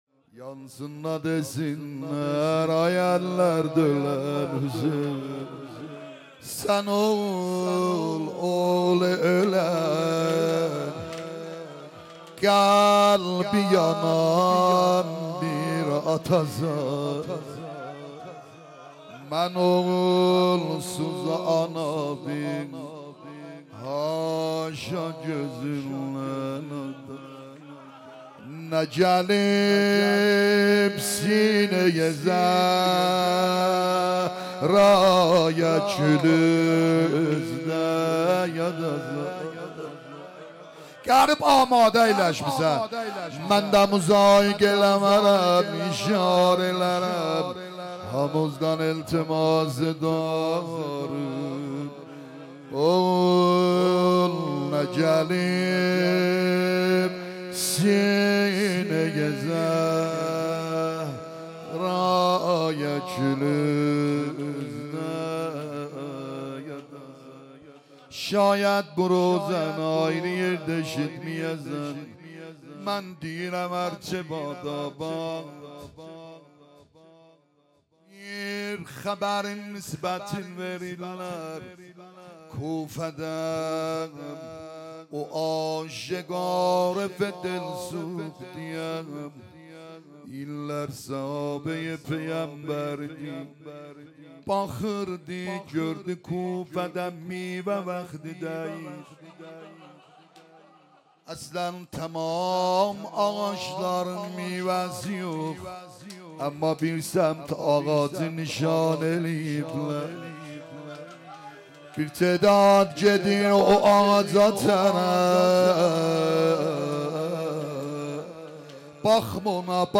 روضه ترکی